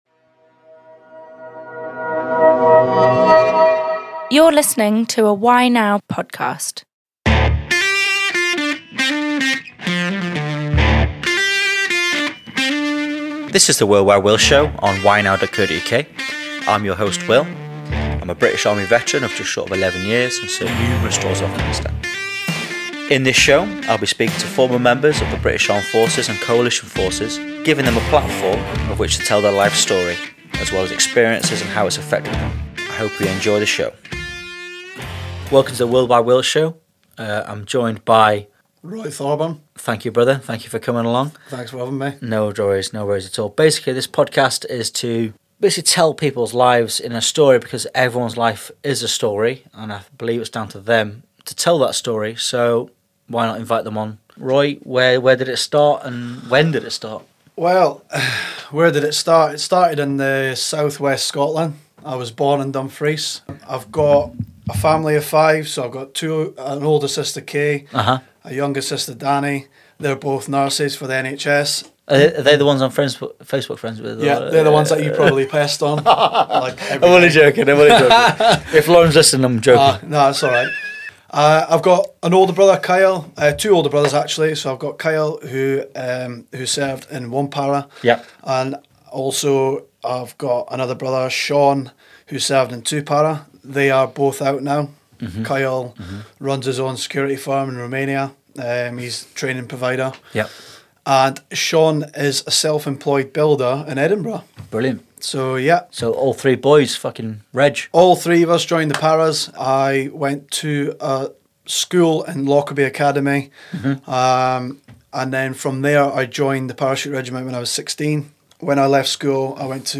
chats to fellow veterans about their lives and experiences.